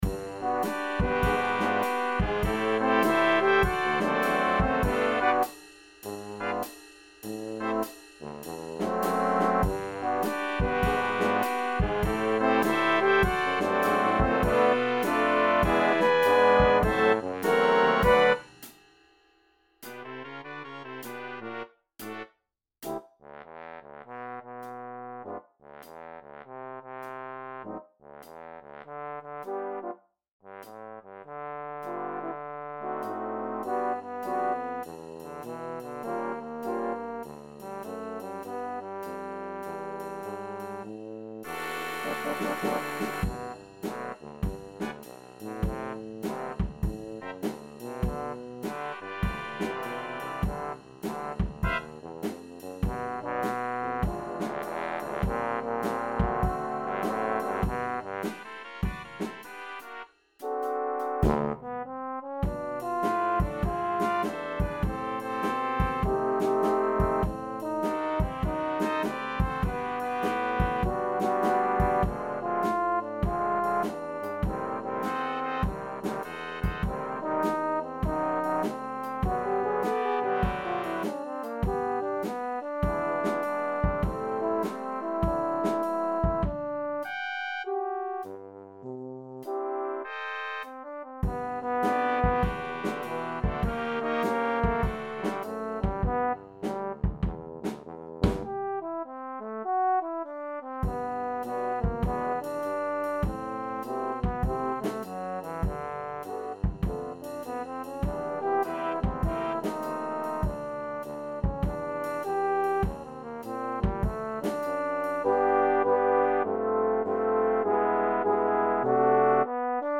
is a jazz and blues song